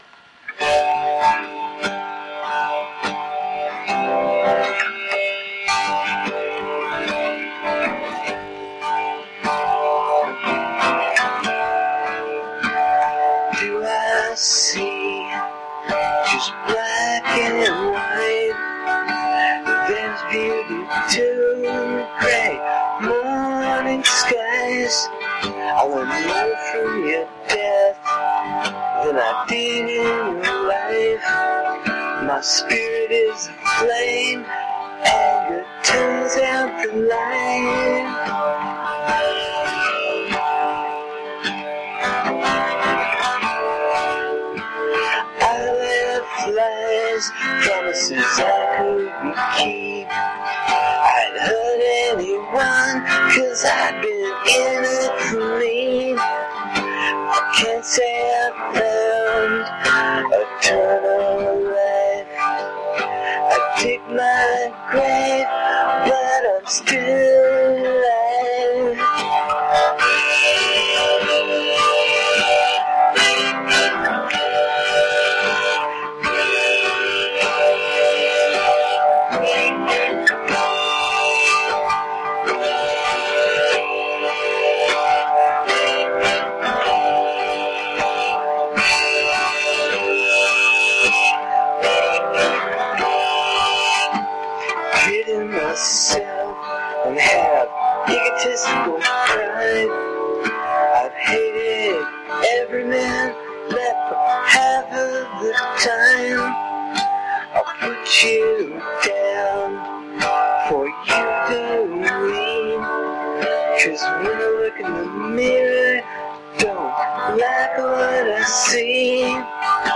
Love the guitar humming. Its great singer/songwriter sounds.